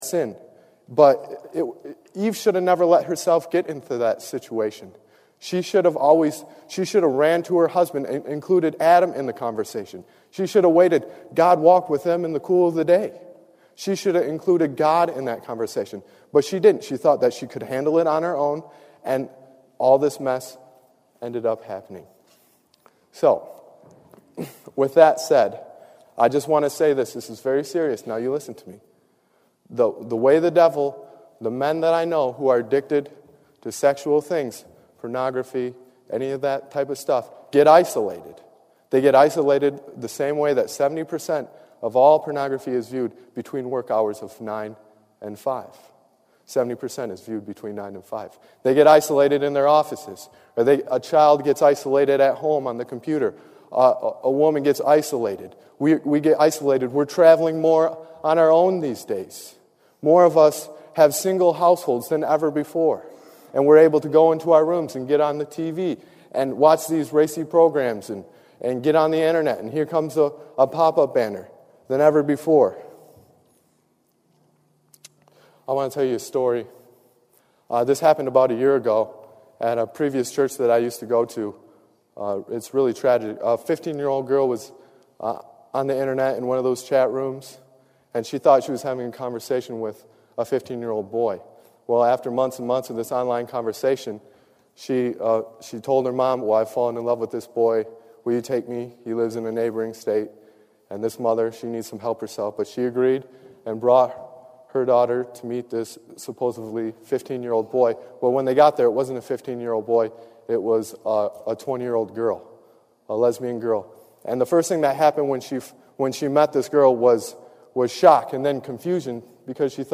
Tags: Sermon Preaching Jesus Bible God